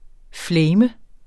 Udtale [ ˈflεjmə ]